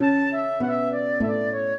flute-harp
minuet5-10.wav